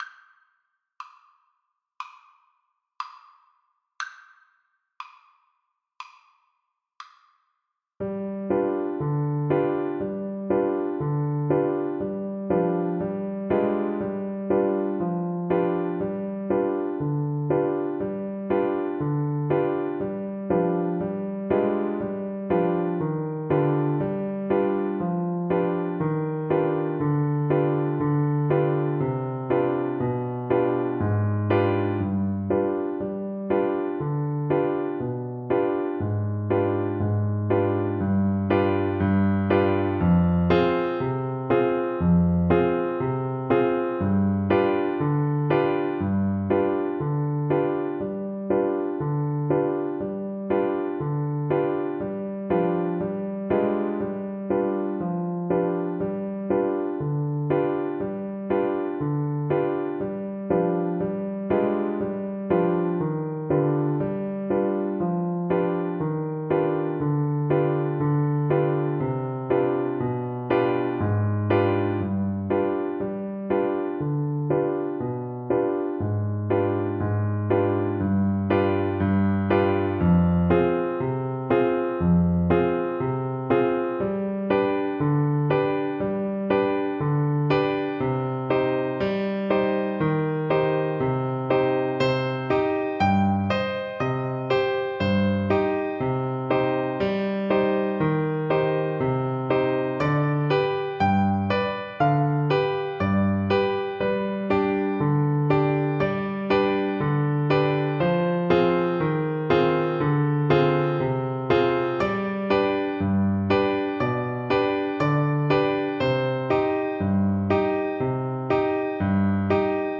Wedding Wedding Clarinet Sheet Music Sherele Mazel Tov
Clarinet
4/4 (View more 4/4 Music)
Moderato
Clarinet pieces in C minor
Free Israeli Sheet Music